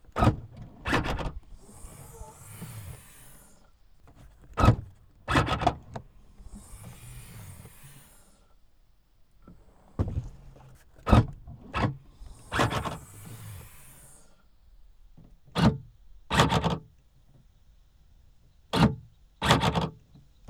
Audi A3 8L 1.9 TDI foley exterior trunk open mechanism mono.wav